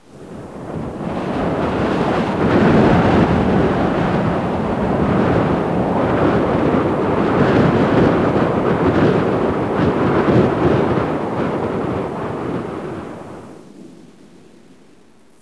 звуком сильных порывов ветра.
Winter_Ridge_strong_windgust.wav